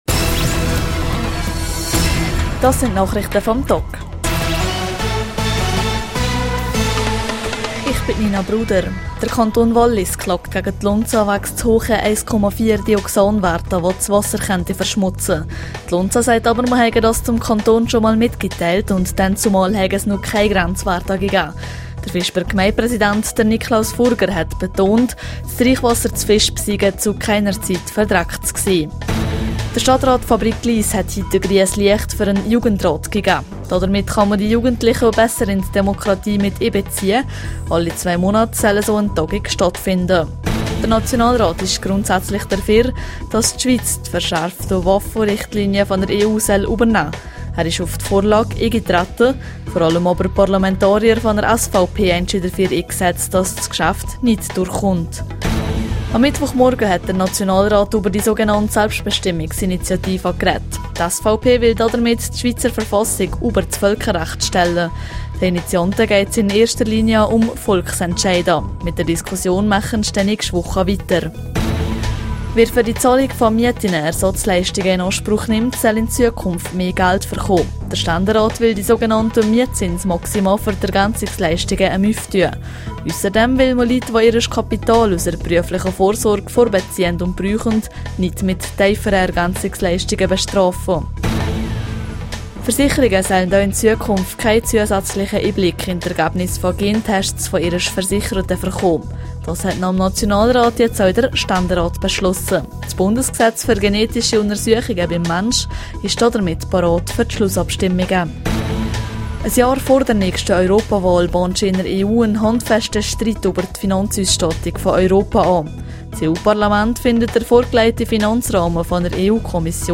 Nachrichte vam Tag (2.2MB)